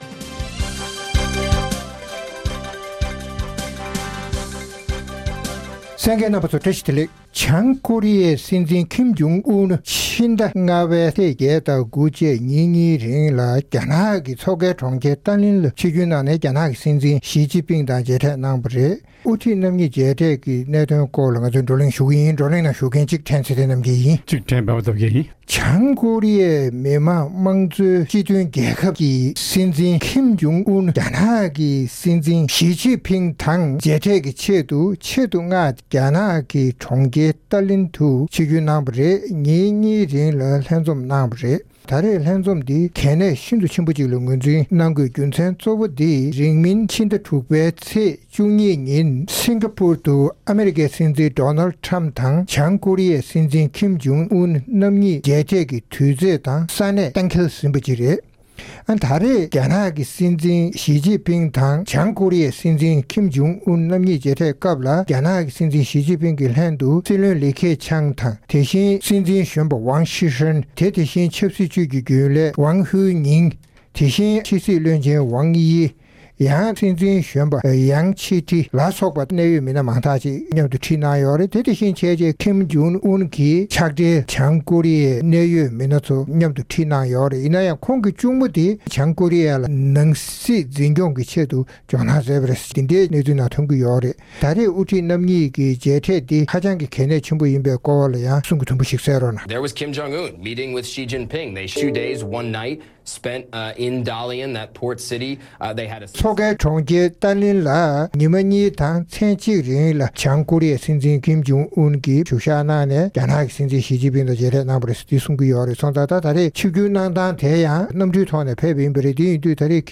རྩོམ་སྒྲིག་པའི་གླེང་སྟེགས་ཞེས་པའི་ལེ་ཚན་ནང་། བྱང་ཀོ་རི་ཡའི་སྲིད་འཛིན་ཀིམ་ཇོང་ཨུན་Kim Jong-Un གྱིས་རྒྱ་ནག་གི་མཚོ་ཁའི་གྲོང་ཁྱེར་ཏ་ལེན་དུ་གནམ་ཐོག་ཕེབས་ཏེ་ཕྱི་ཟླ་༥་པའི་ཚེས་༨་དང་༩་བཅས་ཉིན་གཉིས་རིང་རྒྱ་ནག་གི་སྲིད་འཛིན་ཞི་ཅིན་ཕིང་དང་སླར་ཡང་ལྷན་འཛོམས་གནང་བའི་གནད་དོན་སྐོར་རྩོམ་སྒྲིག་འགན་འཛིན་རྣམ་པས་བགྲོ་གླེང་གནང་བ་གསན་རོགས་གནང་།